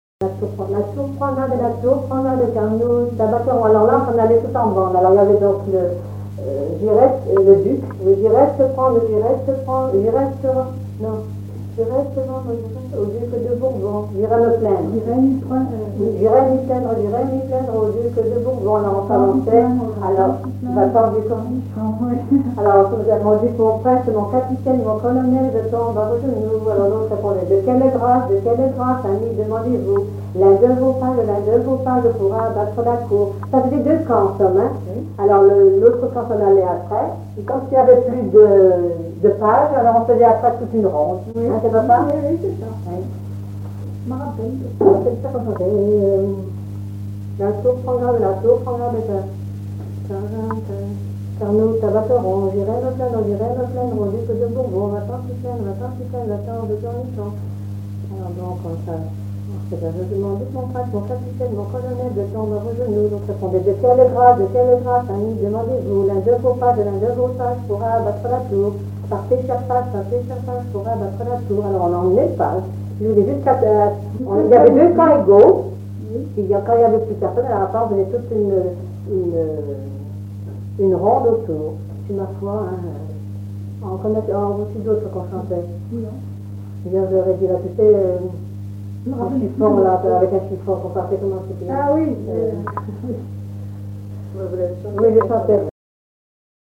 rondes enfantines
Pièce musicale inédite